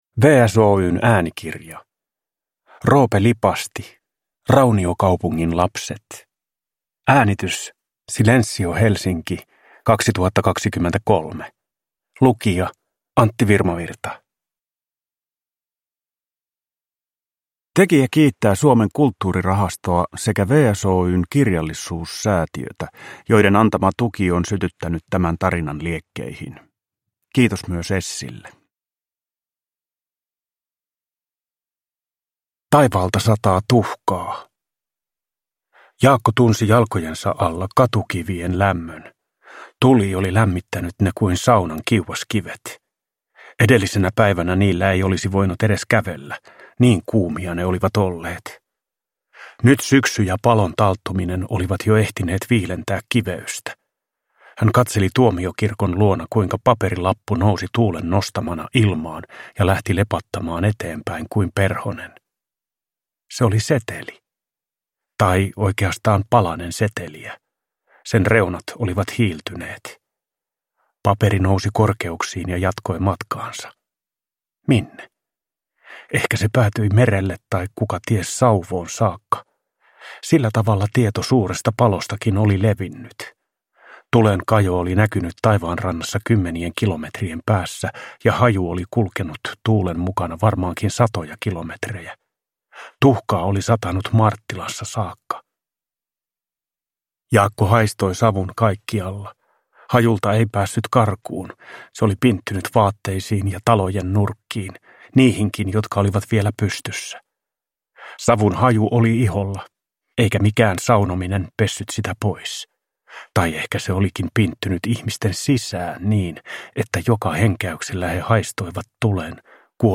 Rauniokaupungin lapset – Ljudbok – Laddas ner
Uppläsare: Antti Virmavirta